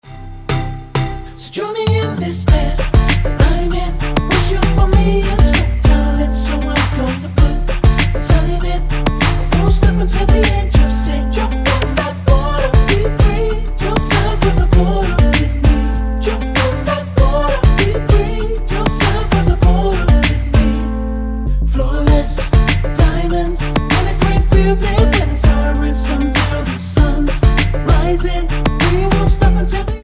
--> MP3 Demo abspielen...
Tonart:B mit Chor